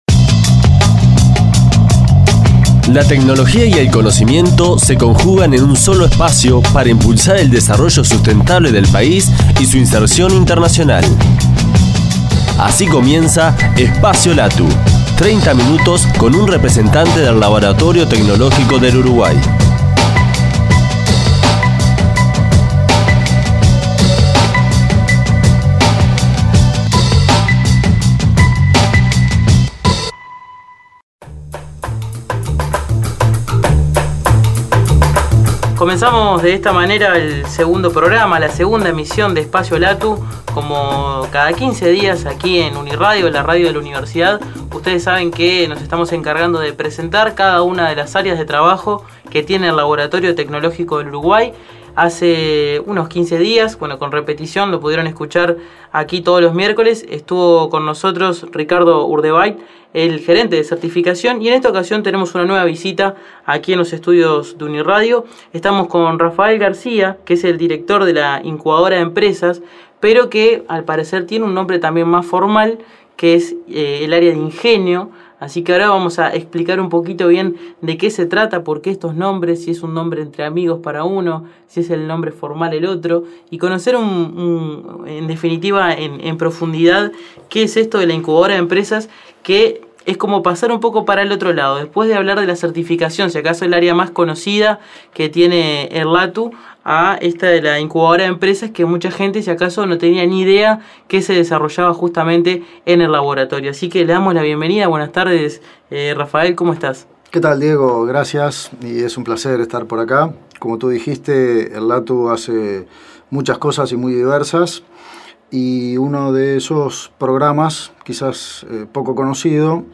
En la entrevista, hablamos del proceso que atraviesa una propuesta hasta ingresar al programa, y el acompañamiento que se le da durante los dos primeros años.